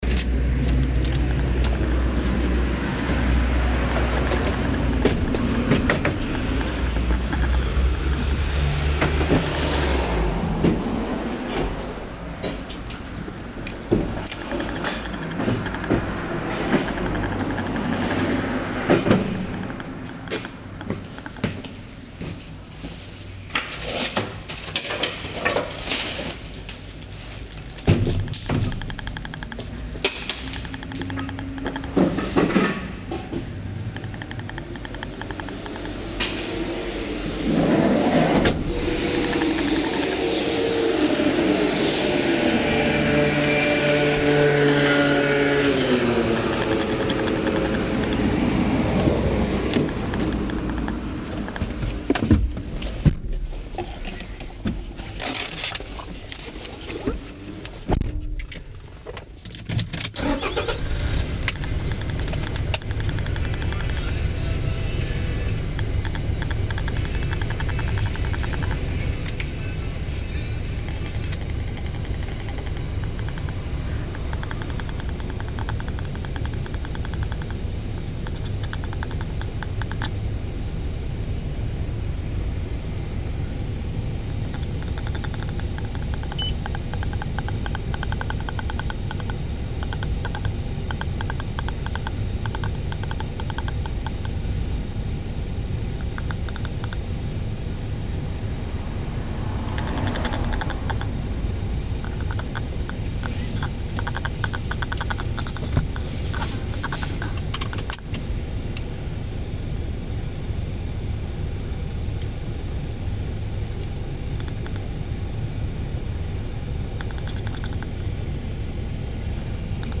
Each musician selects between 8 - 10 minutes of sounds.
1) may act as foreground or background